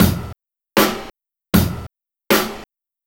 Track 11 - Kick Snare Beat 01.wav